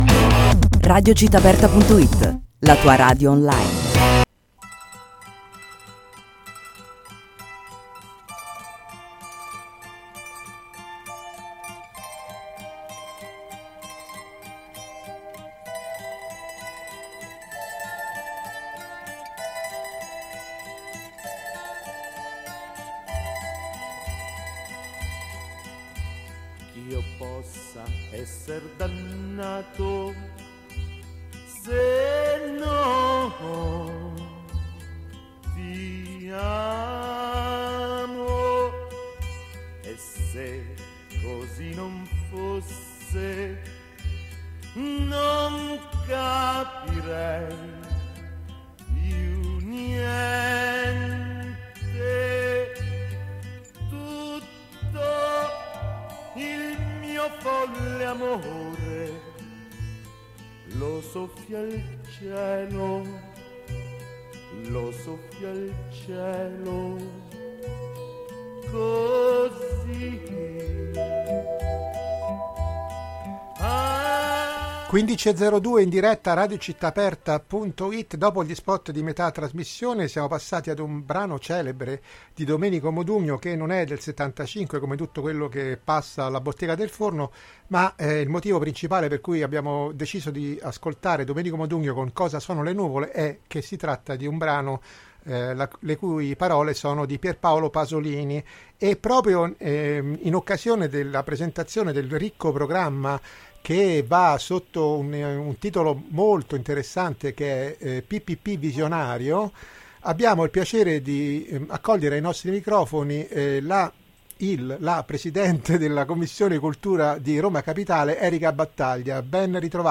PPP Visionario: Intervista a Erica Battaglia | Radio Città Aperta